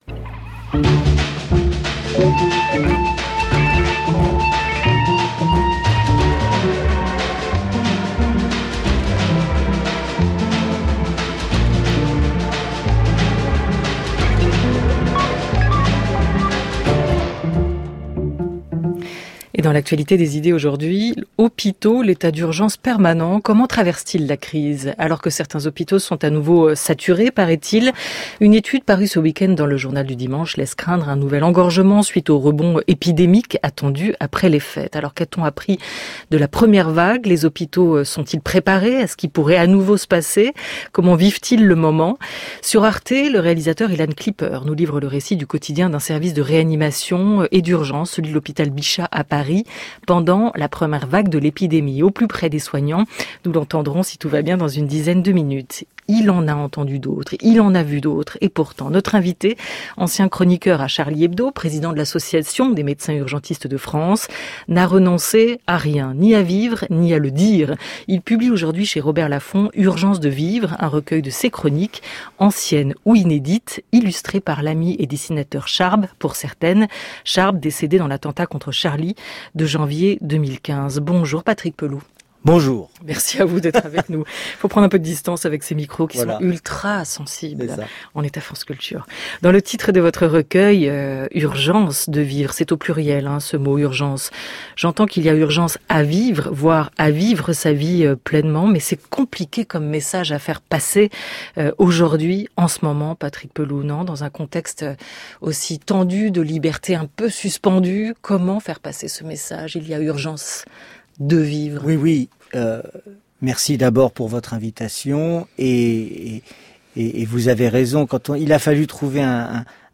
Interview avec Patrick Pelloux le 21 Déc. 2020 sur France Culture